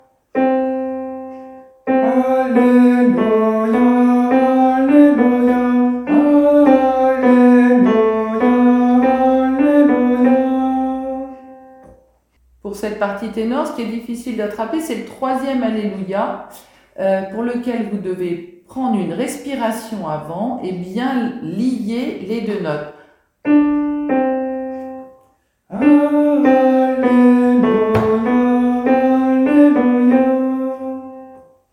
Paroles : Liturgie Catholique Romaine (Kyrie et Alléluia) / A.E.L.F. Musique : Communauté de l’Emmanuel (A. Dumont)
Polyphonies et voix disponibles: